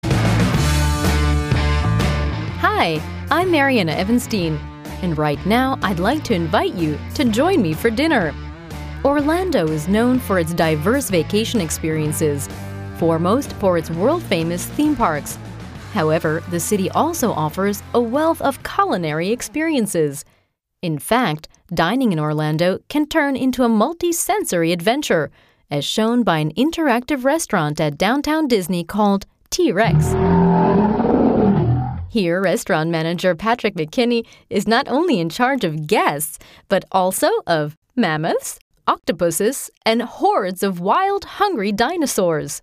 englisch (us)
Sprechprobe: eLearning (Muttersprache):